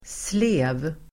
slev substantiv, ladle Uttal: [sle:v] Böjningar: sleven, slevar Synonymer: sked, soppslev Definition: större sked med långt skaft Sammansättningar: soppslev (soup ladle) ladle substantiv, skopa , slev